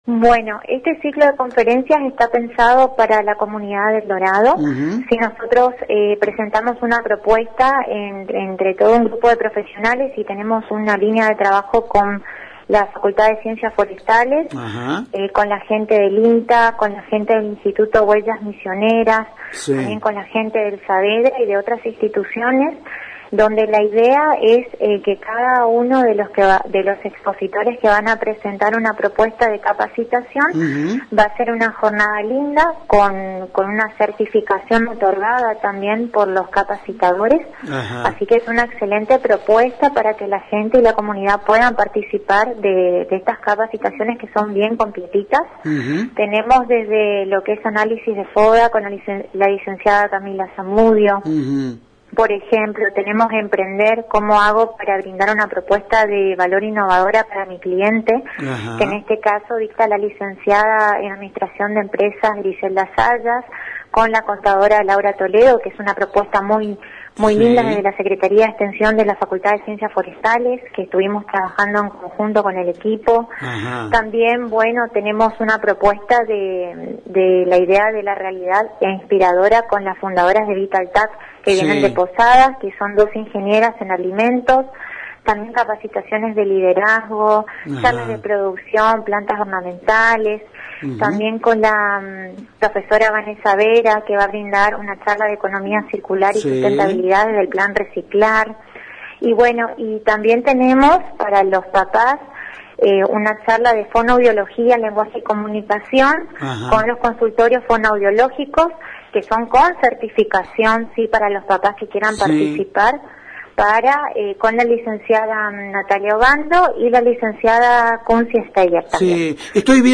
En comunicación telefónica con ANG y Multimedios Génesis